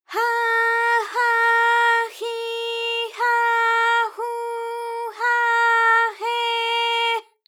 ALYS-DB-001-JPN - First Japanese UTAU vocal library of ALYS.
ha_ha_hi_ha_hu_ha_he_h.wav